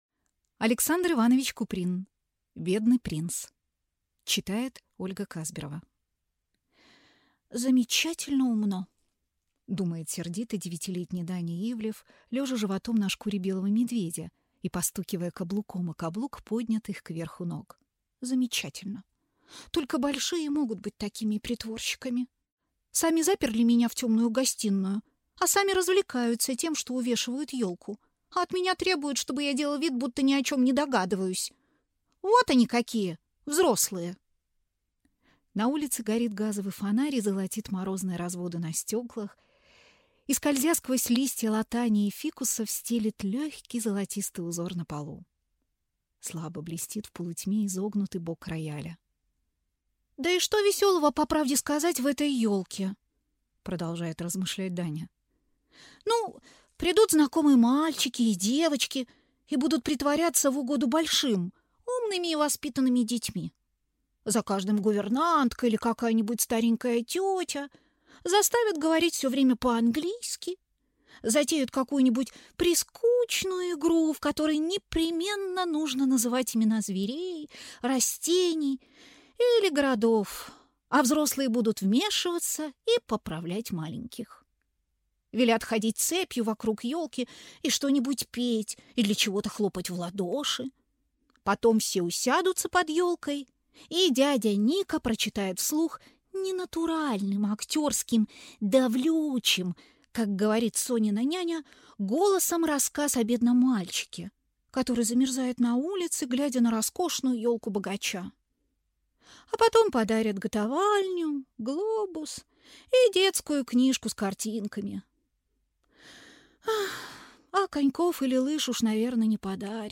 Аудиокнига Бедный принц | Библиотека аудиокниг